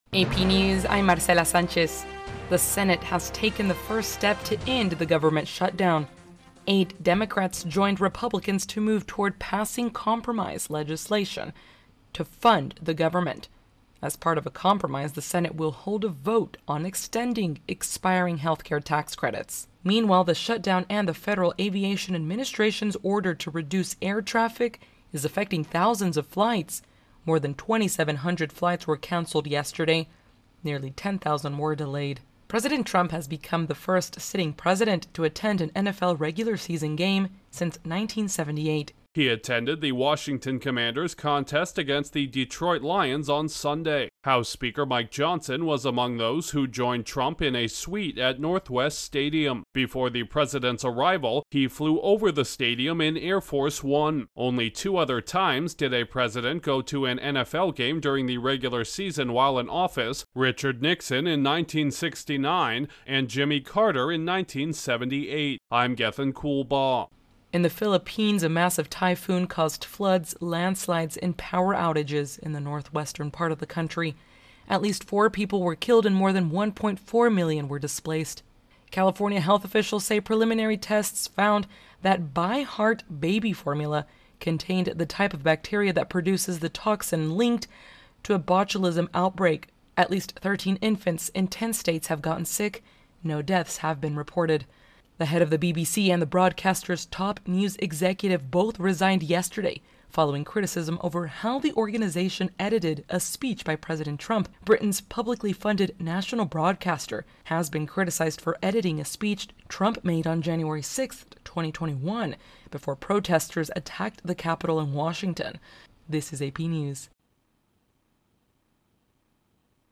AP Hourly NewsCast